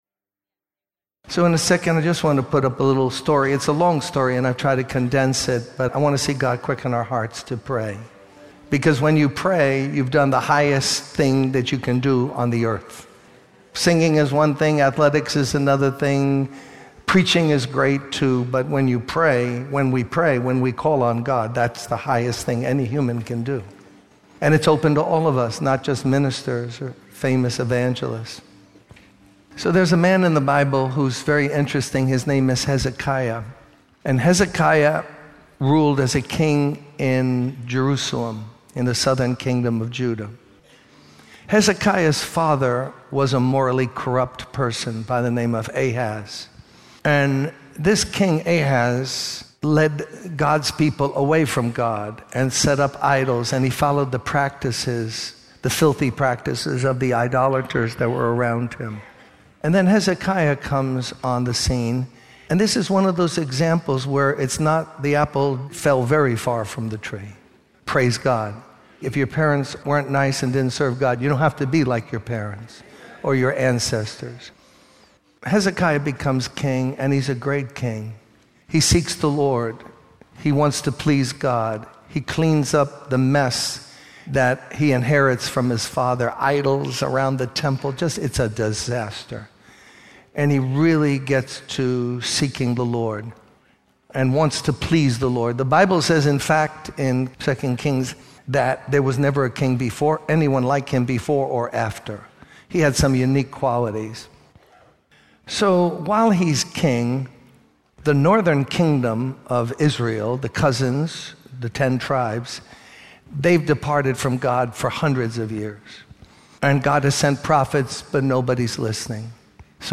In this sermon, the preacher addresses the challenges and attacks that the church is currently facing.